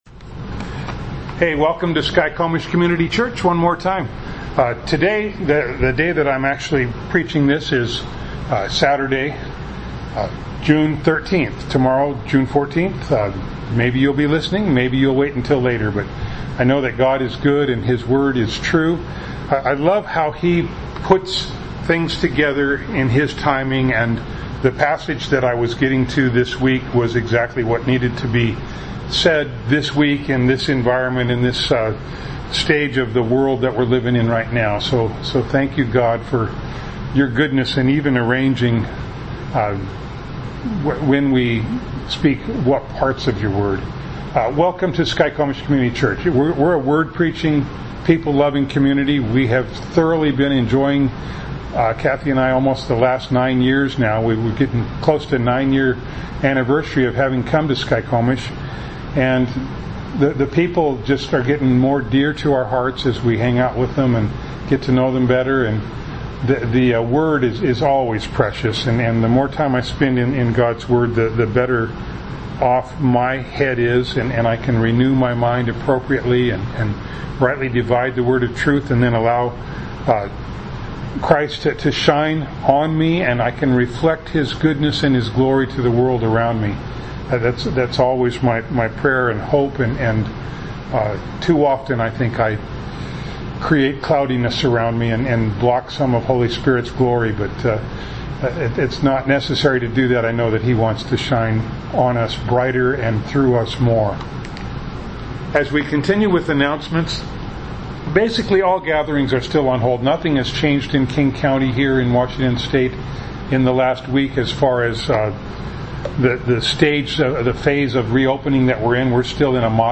Ephesians 5:14 Service Type: Sunday Morning Bible Text